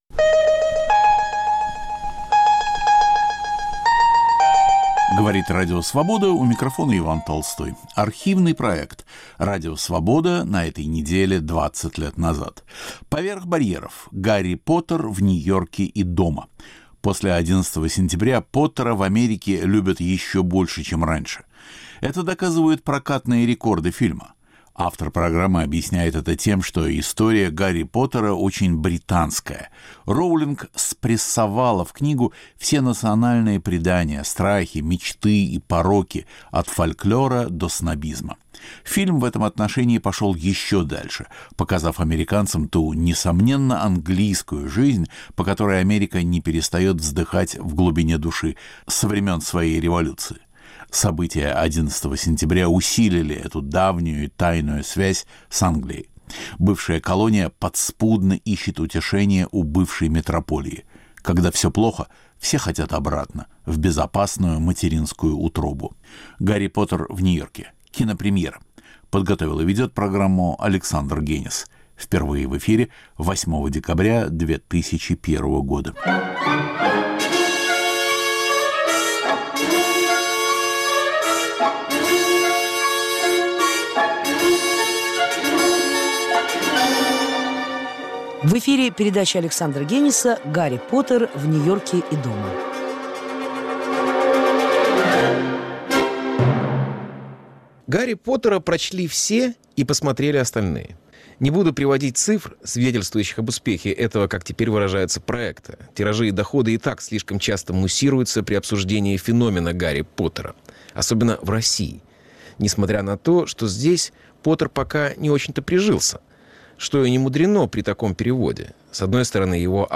Подготовил и ведет Александр Генис. Памяти Кена Кизи.
Поет Боб Дилан.